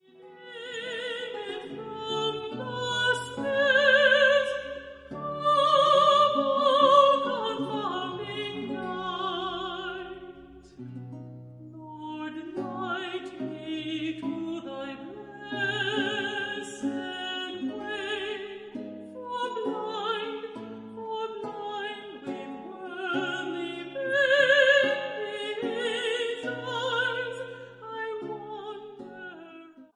'Author of Light'. (song)
Excerpt from the album Brian Asawa: The Complete RCA Recordings: